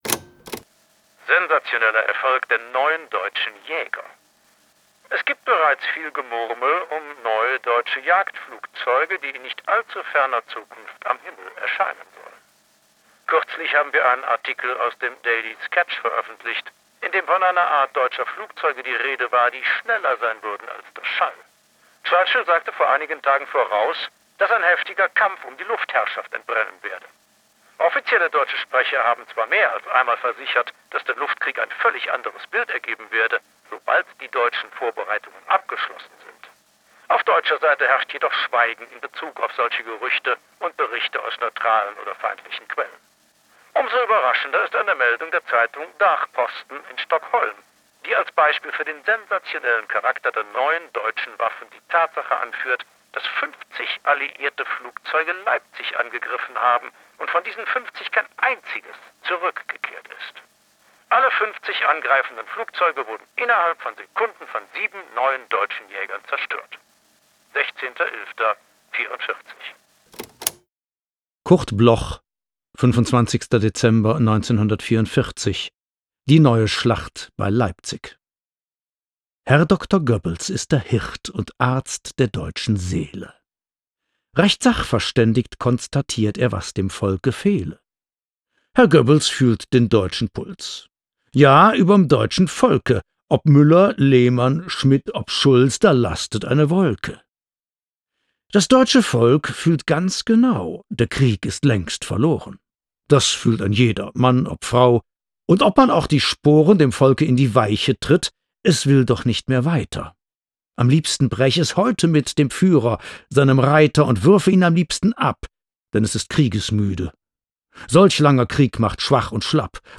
vorgetragen von Frank Schätzing